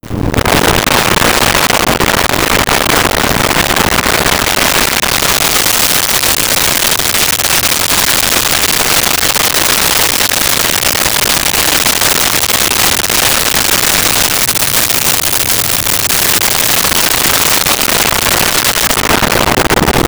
Motorcycle In Idle Away
Motorcycle In Idle Away.wav